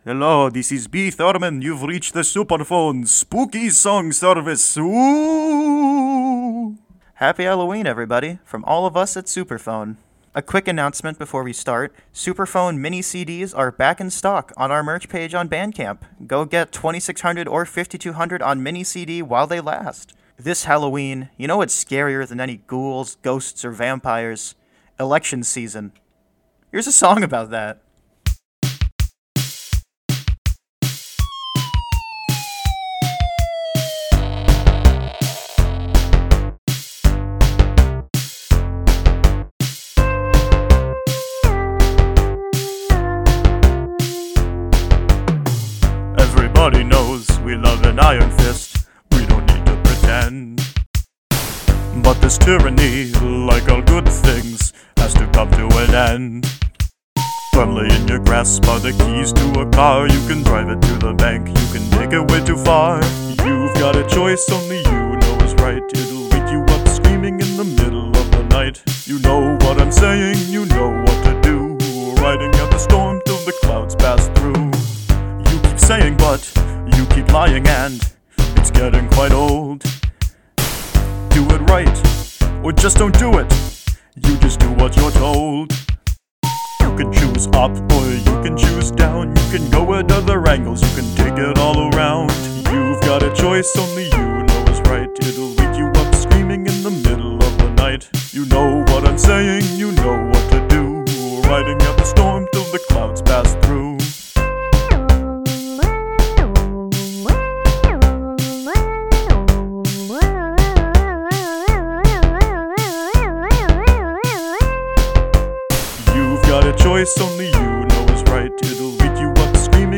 Live Cover